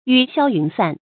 雨消云散 yǔ xiāo yún sàn
雨消云散发音